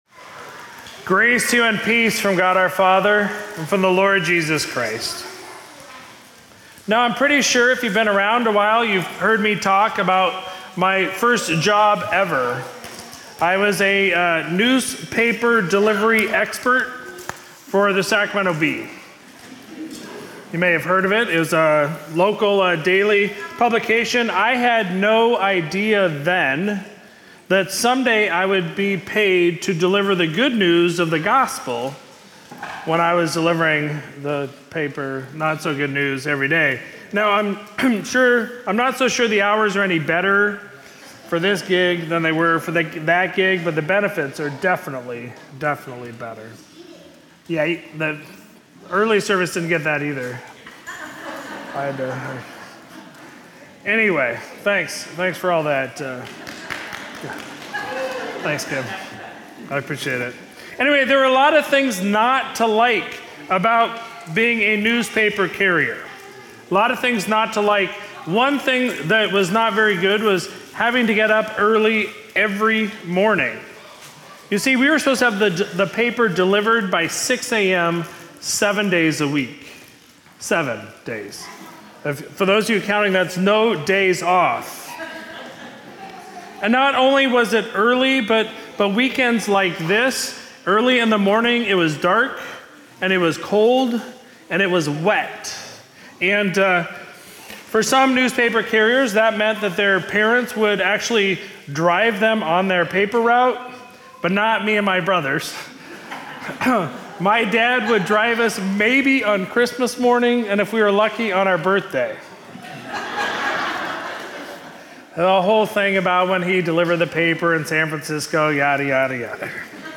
Sermon from Sunday, March 3, 2024